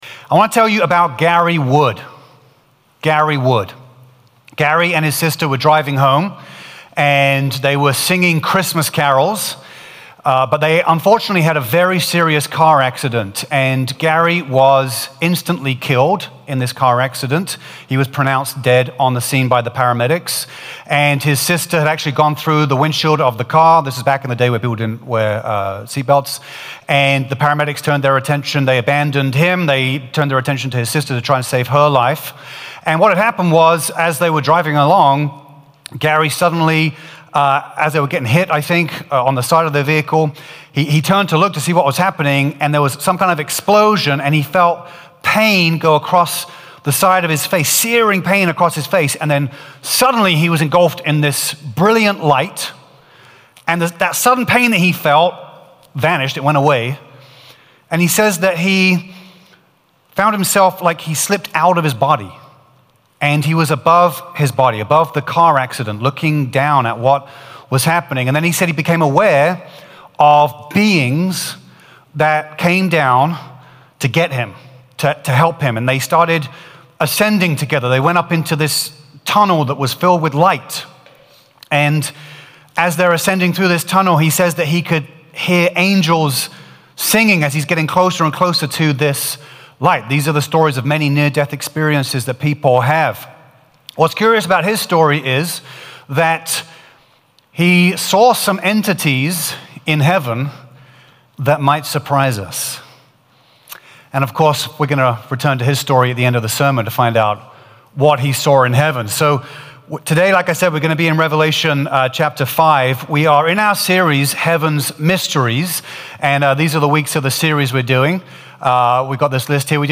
A message from the series "Heaven's Mysteries."